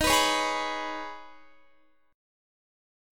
Listen to Eb7sus4#5 strummed